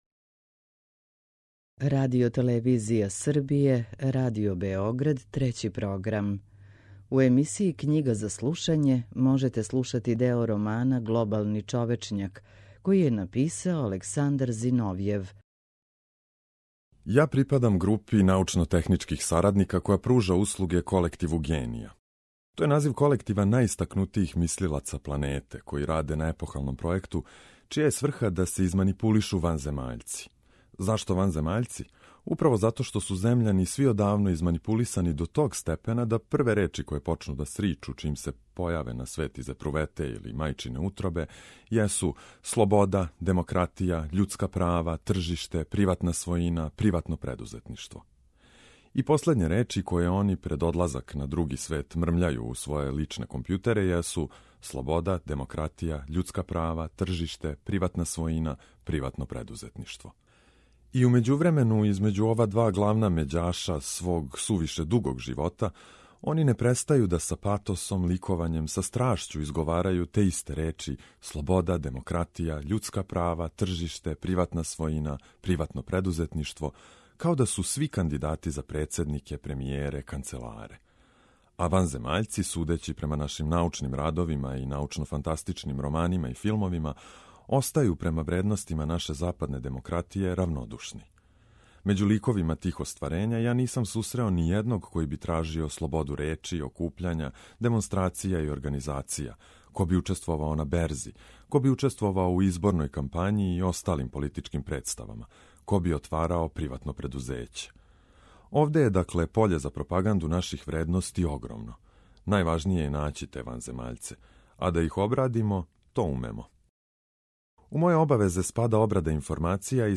У емисији Књига за слушање можете пратити делове романа Александра Зиновјева „Глобални човечњак”.